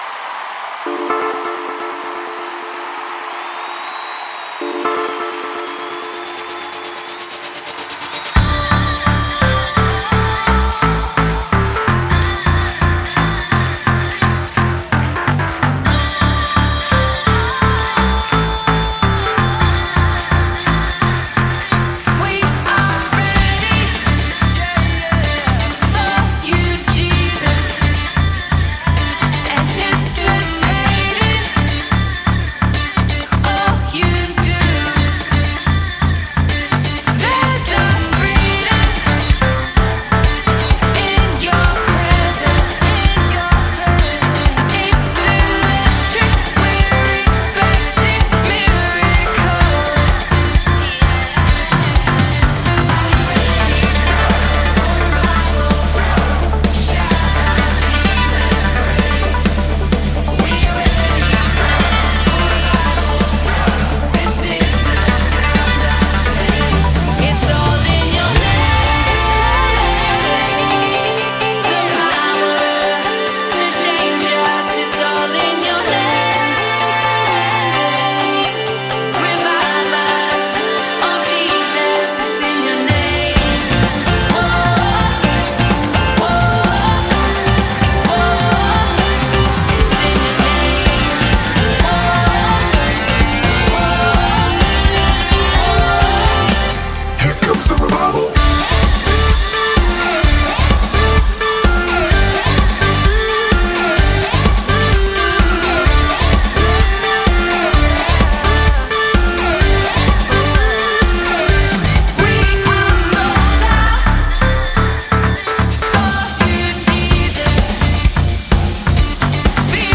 107 просмотров 144 прослушивания 1 скачиваний BPM: 128